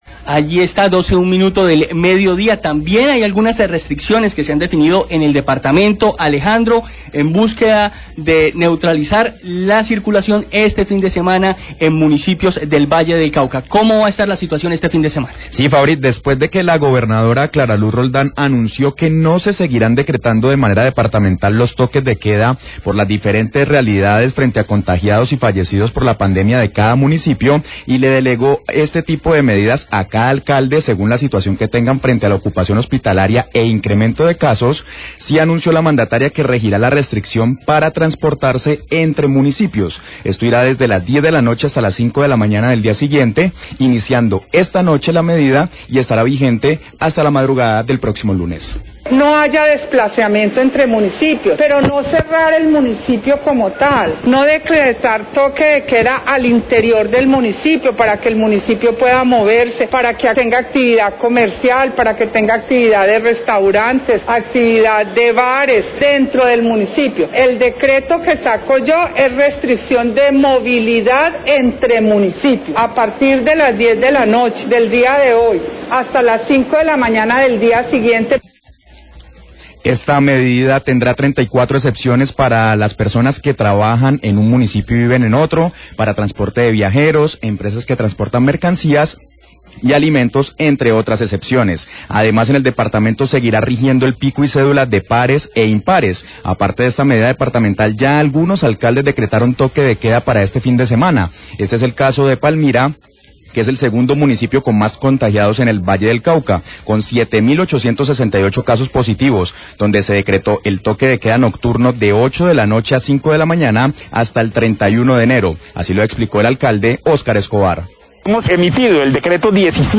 Radio
Tras el anuncio de la Gobernadora del Valle que dejará a los alcaldes de cada municipio la implementación de los horarios de toque de queda, el alcalde de Palmira, Óscar Escobar, habla de las medidas de restricción a la movilidad que impondrá.